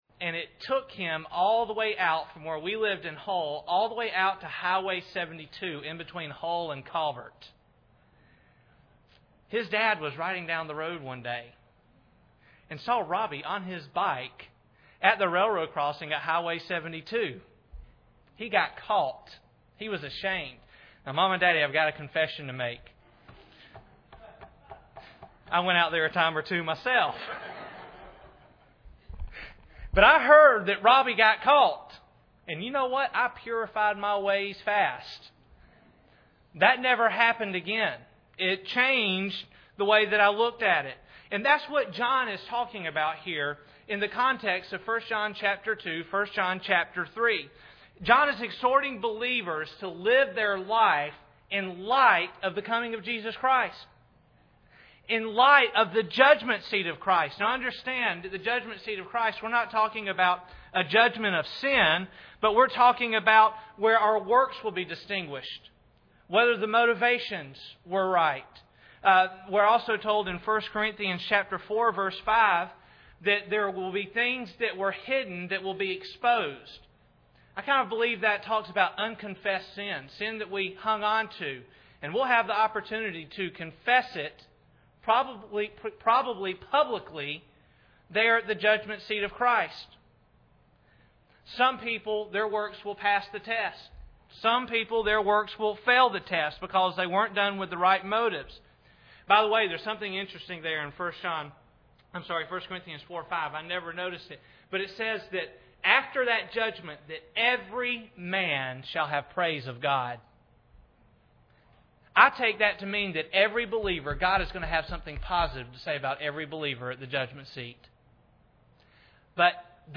Due to a technical difficulty, the first couple of minutes of the sermon was not recorded.